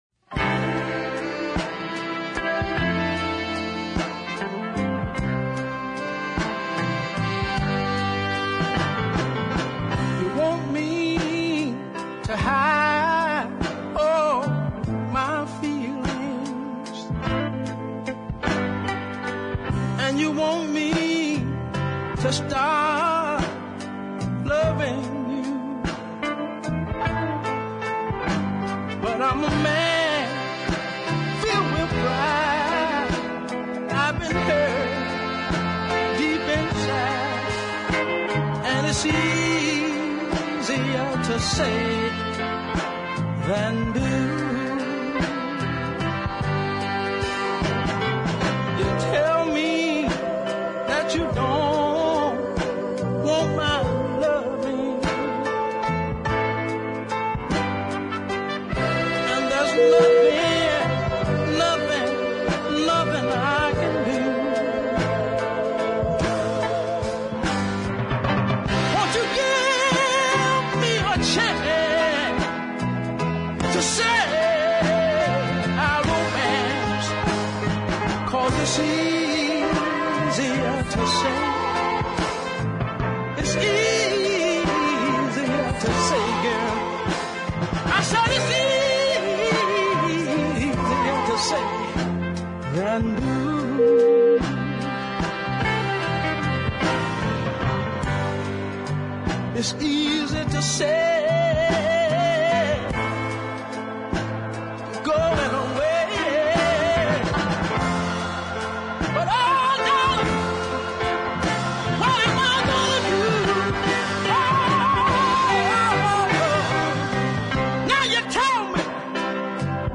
recorded in Tennessee
Love the "open" arrangment too.